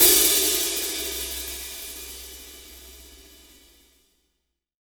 -16  HAT13-R.wav